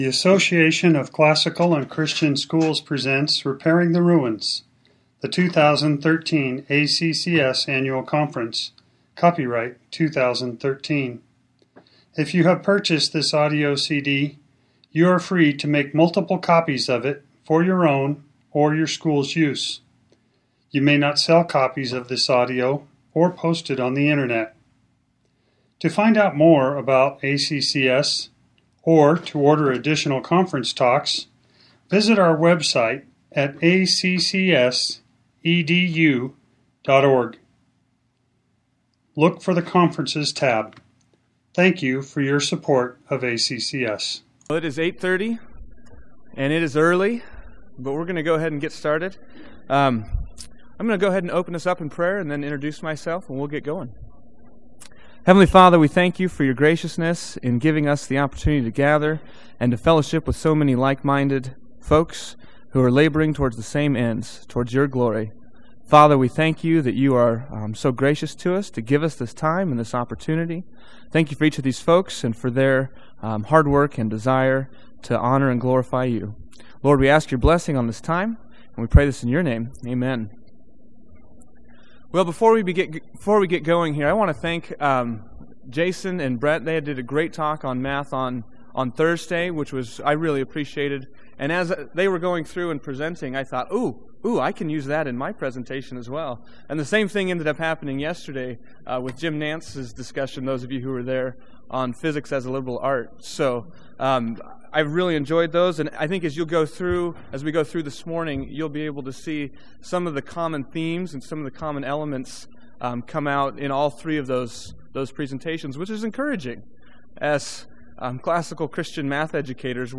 2013 Workshop Talk | 0:59:44 | All Grade Levels, Math, Virtue, Character, Discipline